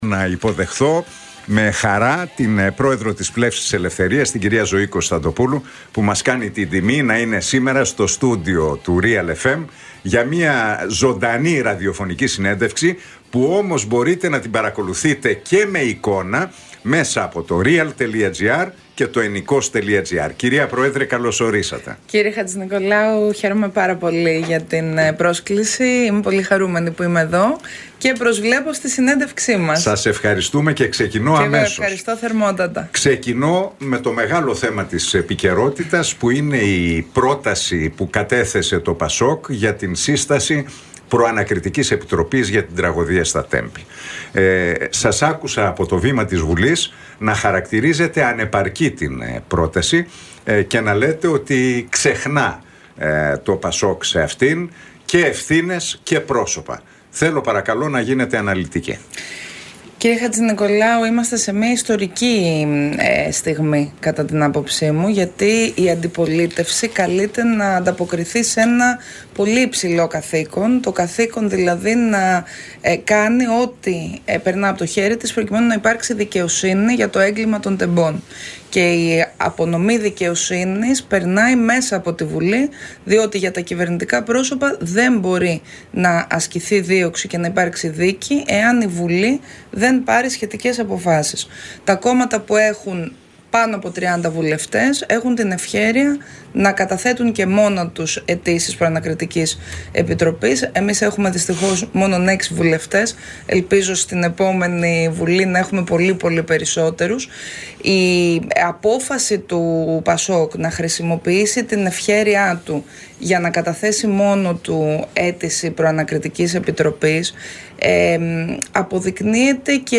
Για την υπόθεση των Τεμπών, την πρόταση του ΠΑΣΟΚ για την σύσταση Προανακριτικής Επιτροπής, τα εθνικά θέματα και την βία στα Πανεπιστήμια μίλησε, μεταξύ άλλων, η πρόεδρος της Πλεύσης Ελευθερίας, Ζωή Κωνσταντοπούλου στην συνέντευξη εφ’όλης της ύλης που παραχώρησε στον Νίκο Χατζηνικολάου από την συχνότητα του Realfm 97,8.